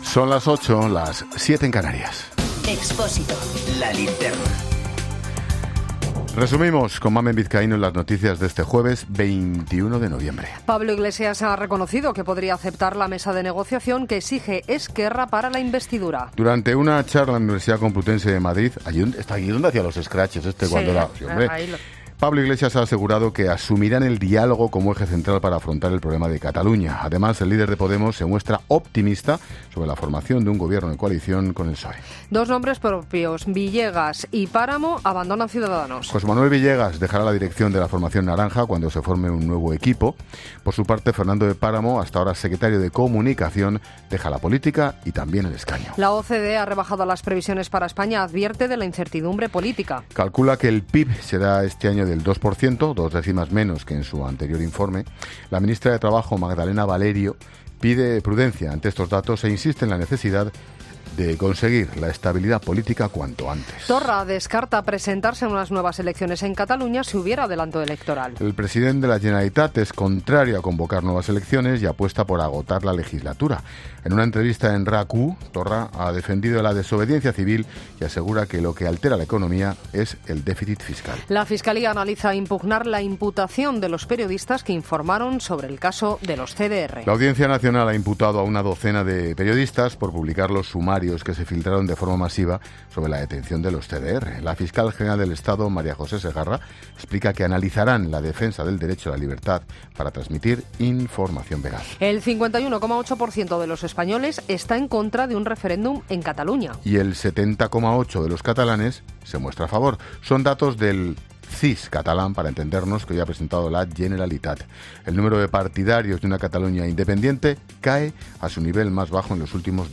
Boletín de noticias COPE del 21 de noviembre a las 20.00 horas